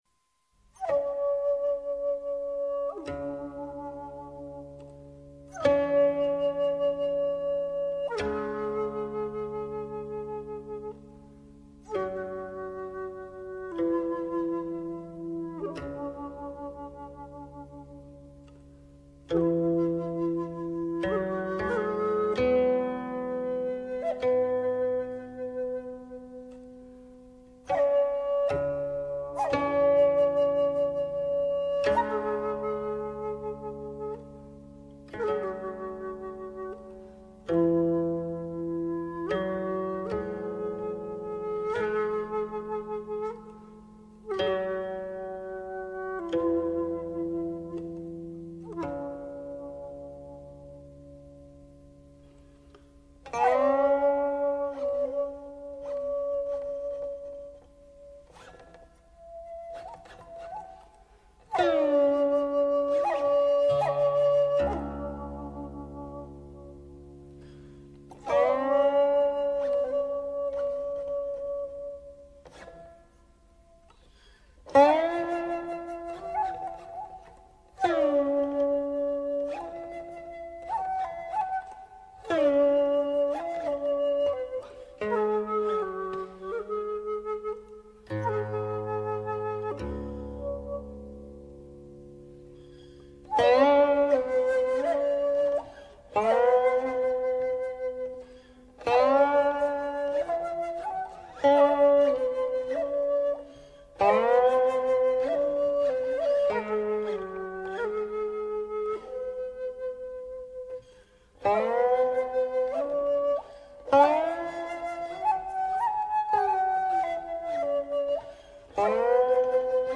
大师典范演绎，名盘呈现，情归丝弦，源自音乐之力量！